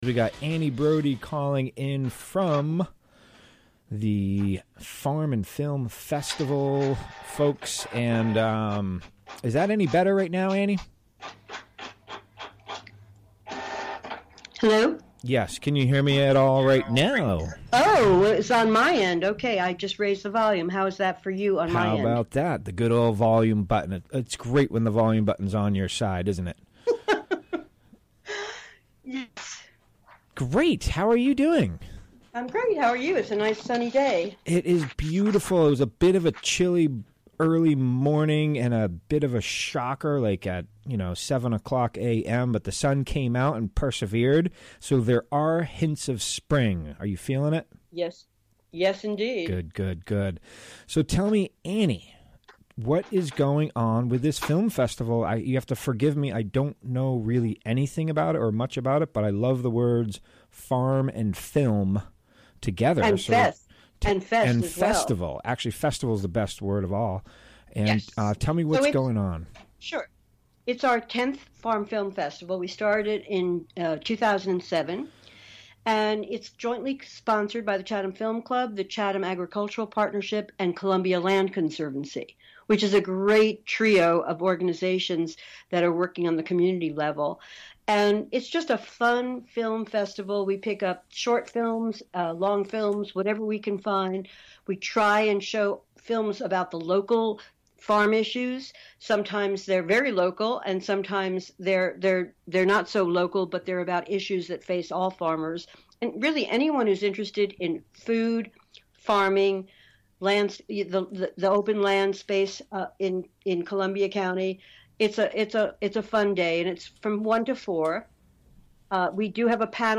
Recorded live on the WGXC Afternoon show on March 19, 2018.